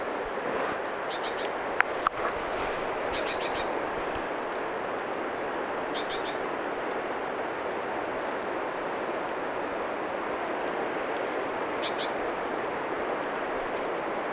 tuolla tavoin huomioääntelevä talitiaislintu
tuolla_tavoin_huomioaanteleva_talitiaislintu.mp3